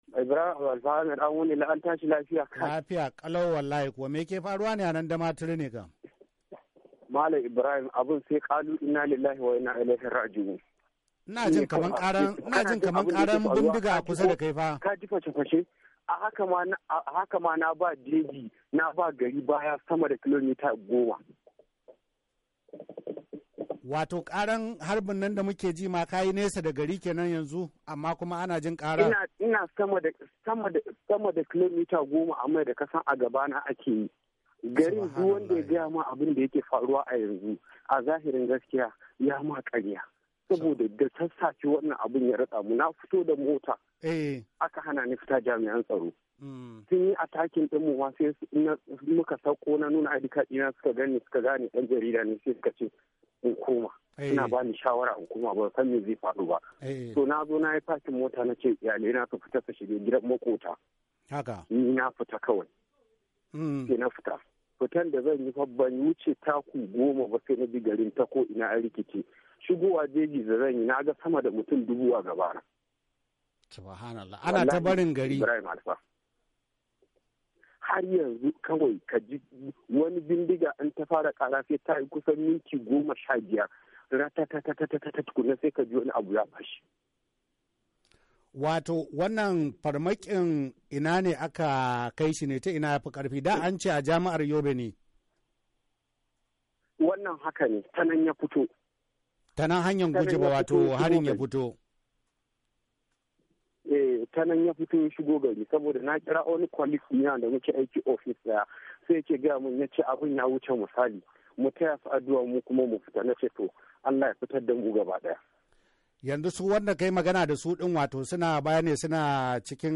Tattaunawa Da Wani Dan Jarioda Yanzun Nan A Damaturu - 4'52"